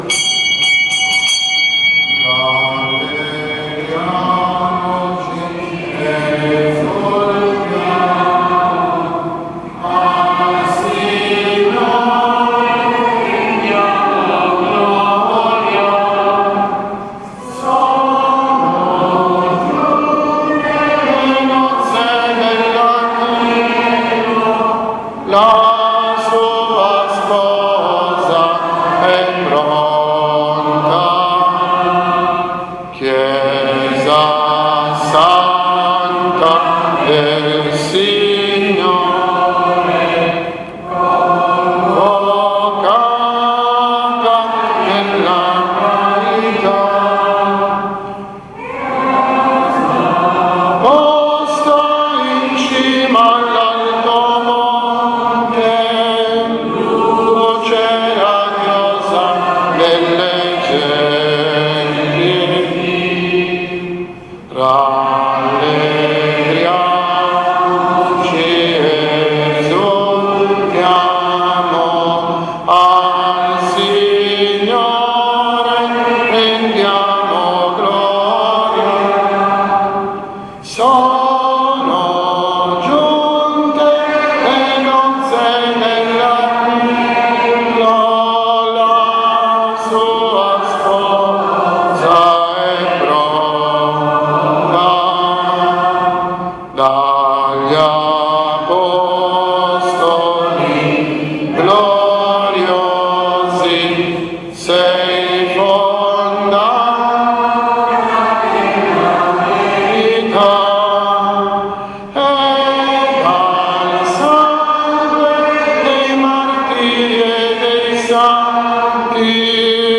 12.06.2017 – S. MESSA IN ONORE DI SANT’ANTONIO DA PADOVA
Santa Messa votiva in onore di Sant'Antonio da Padova, anticipata di un giorno rispetto alla data liturgica.